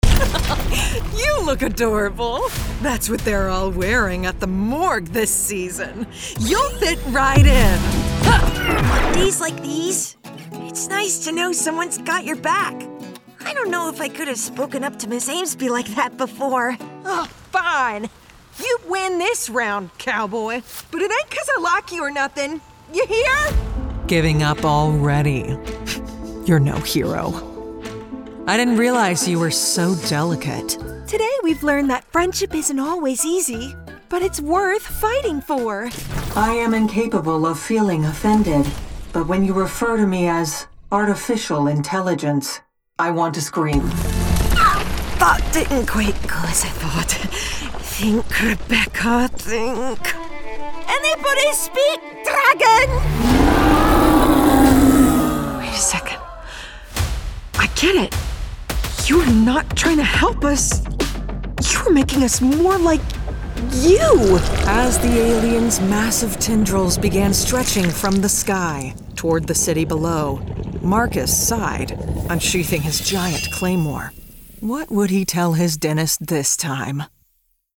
Voice Actors
animation 🎬